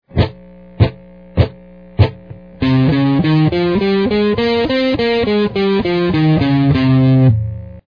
Scales and Modes on the Guitar
C-Phrygian.mp3